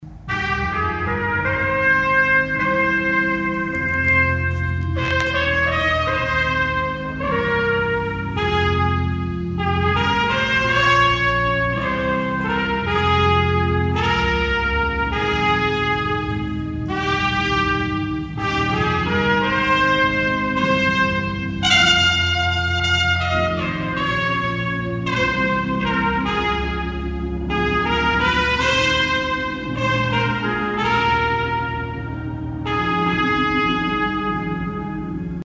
The Negro Leagues and its ties to the Manhattan area were featured as part of the kickoff to Juneteenth weekend festivities Thursday night at the Douglass Recreation Center.
trumpet performance